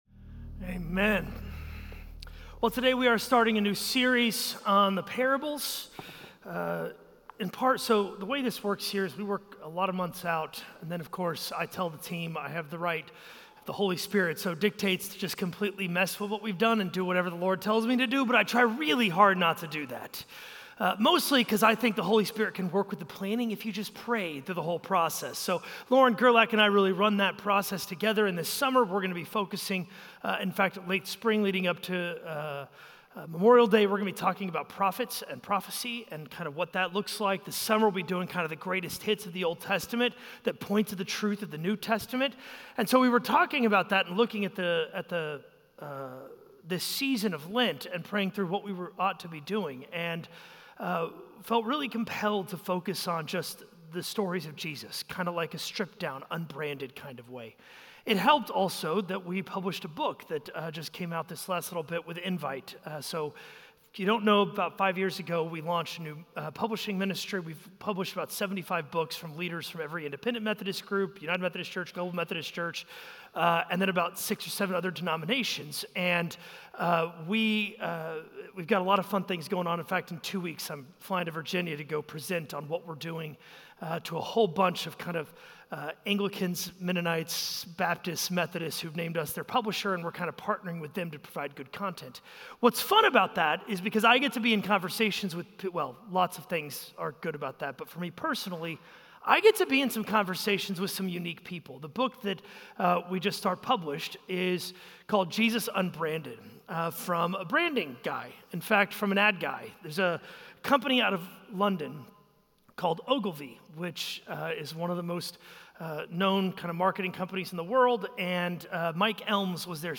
A message from the series "Jesus Parables."